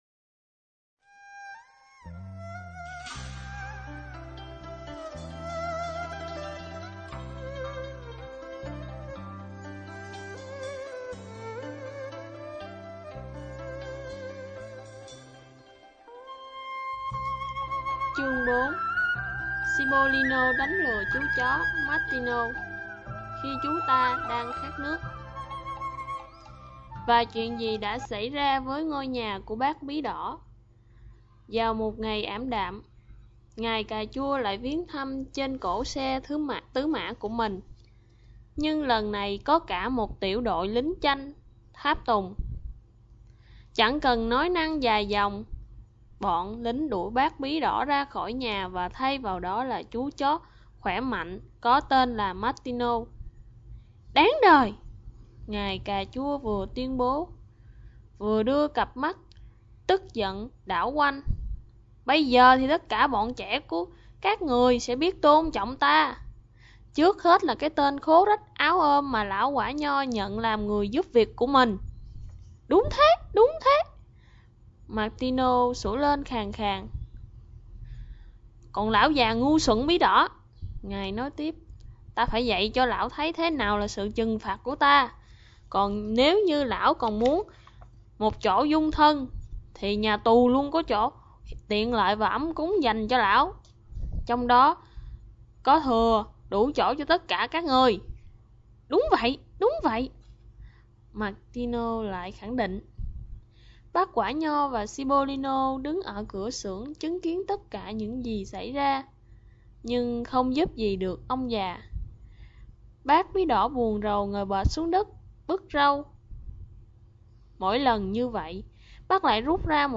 Sách nói | Cuộc Phiêu Lưu Của Chú Hành